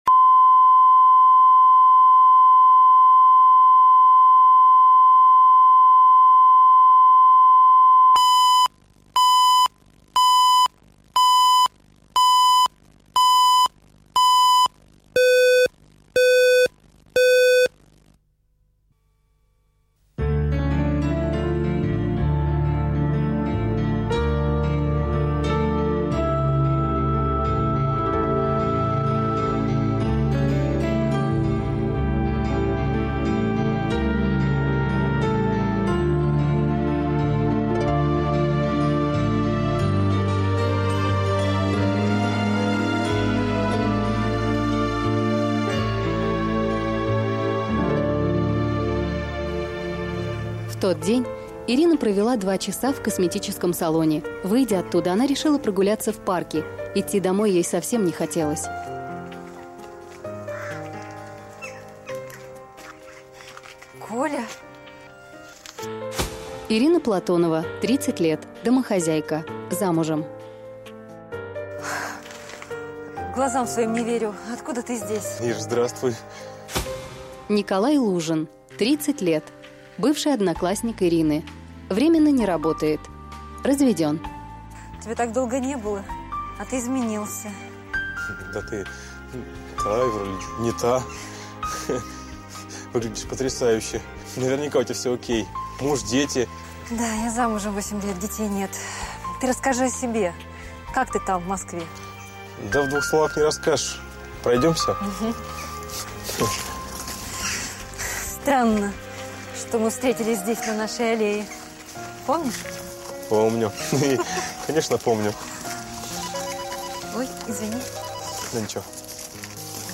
Аудиокнига Лицом к лицу | Библиотека аудиокниг
Aудиокнига Лицом к лицу Автор Александр Левин.